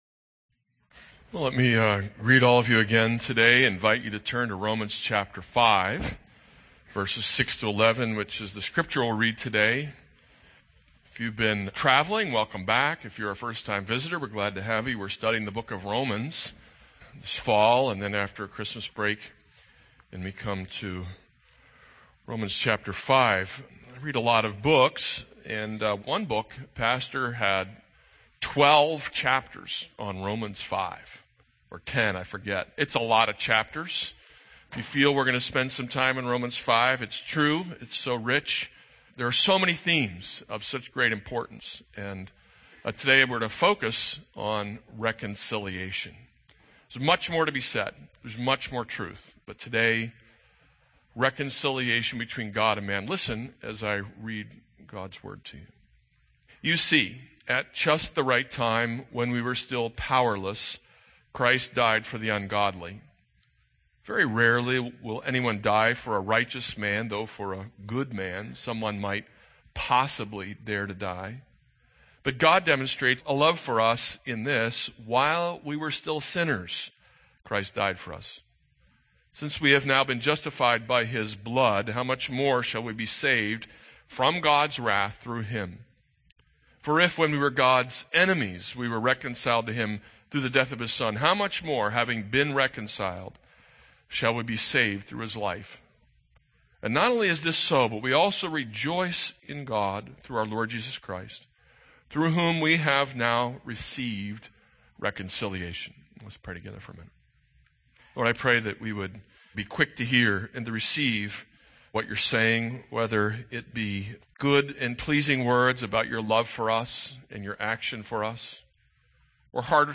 This is a sermon on Romans 5:6-11.